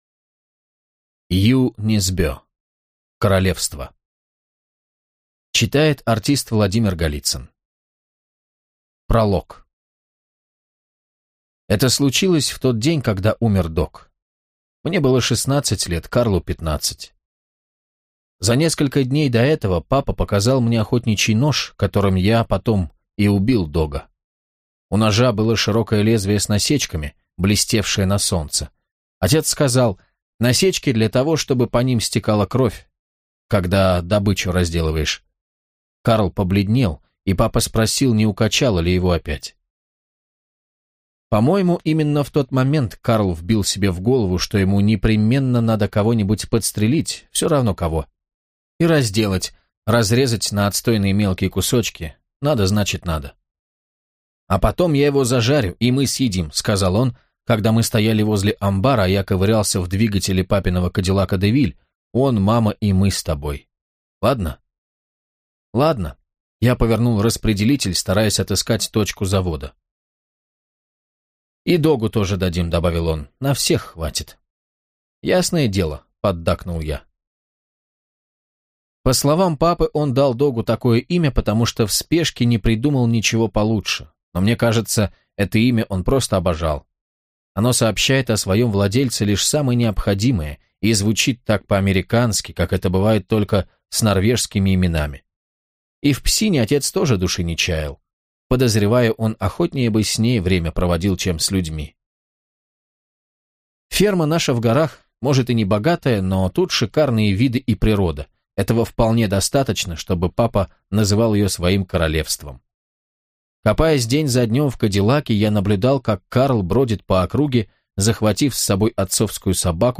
Аудиокнига Королевство - купить, скачать и слушать онлайн | КнигоПоиск